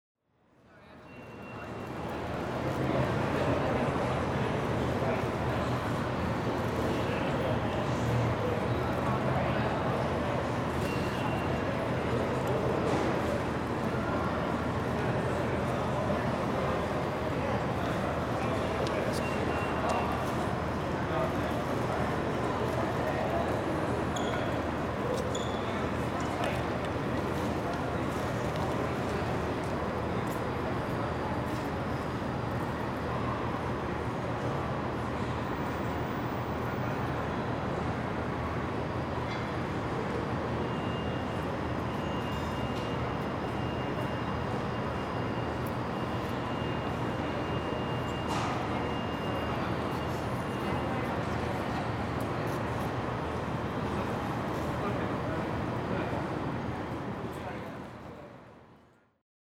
Gemafreie Sounds: Bahnhof
mf_SE-5636-railway_station_in_london.mp3